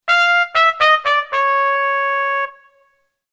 cartoon sound effect Archives
Wha Wha Wha Sound Effect
Wa-wa-wa-sound.mp3